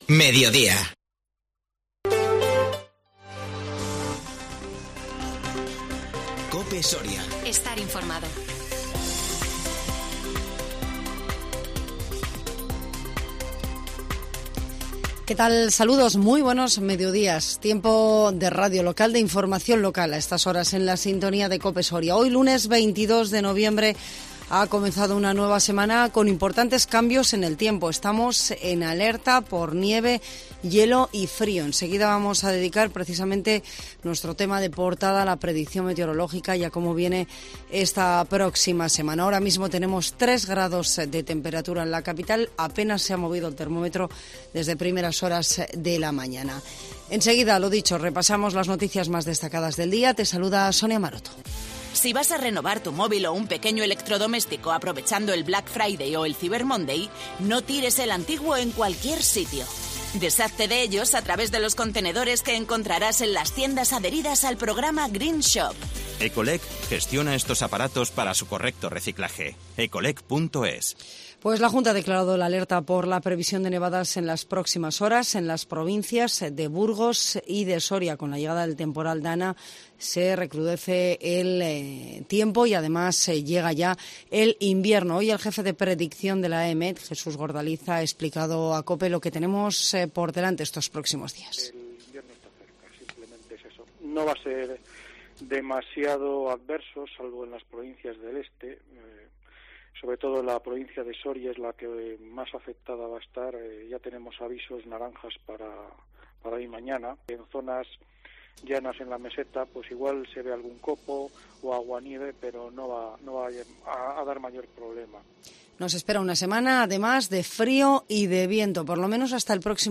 INFORMATIVO MEDIODÍA 22 NOVIEMBRE